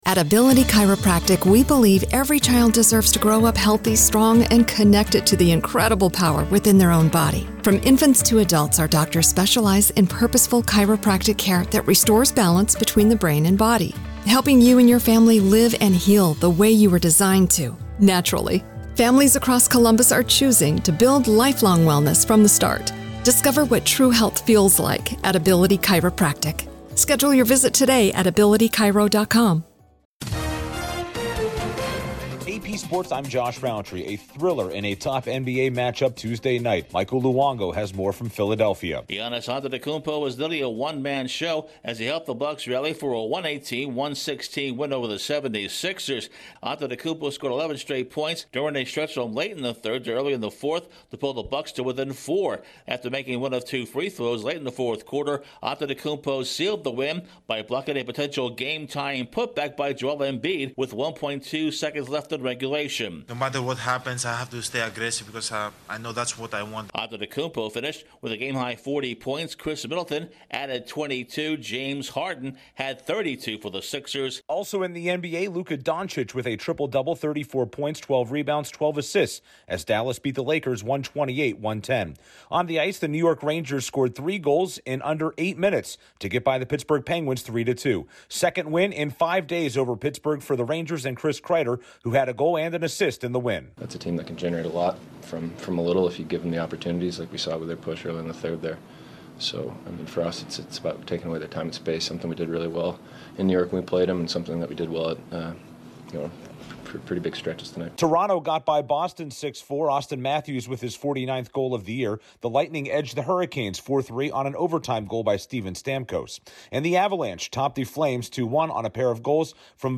Update on the latest sports